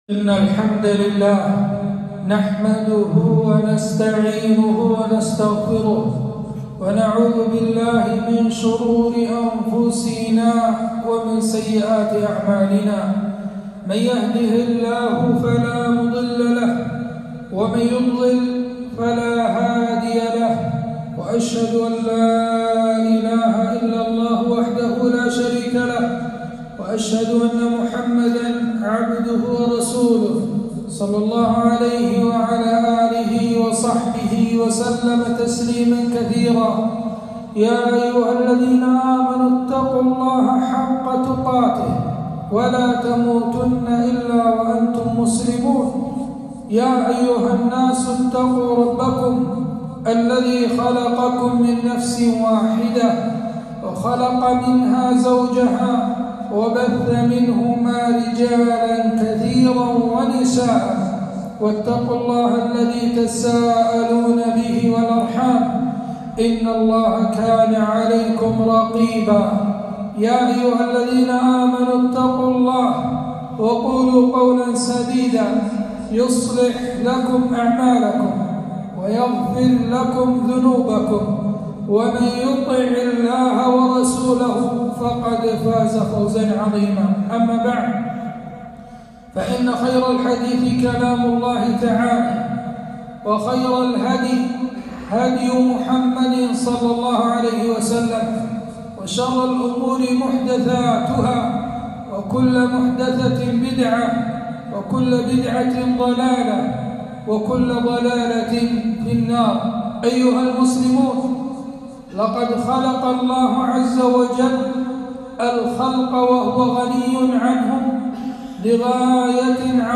خطبة - التمسك بالإسلام والحذر من الشرك والمشركين